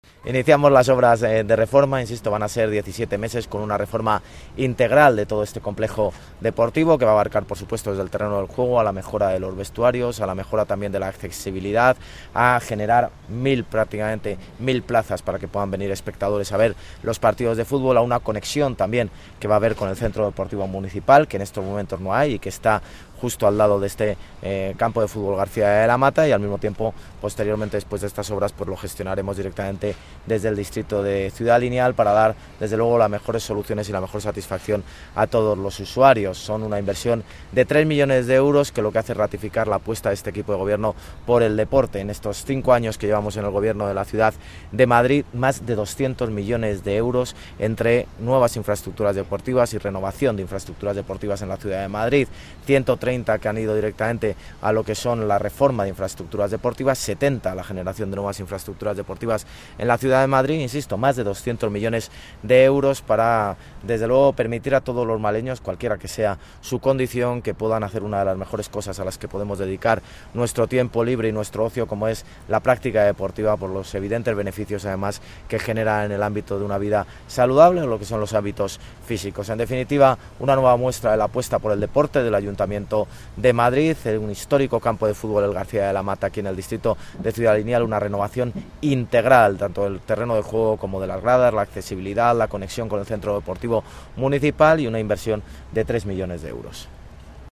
Nueva ventana:Intervención del alcalde de Madrid, José Luis Martínez-Almeida, durante la visita a las obras de reforma del campo de fútbol García de la Mata